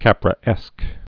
(kăprə-ĕsk)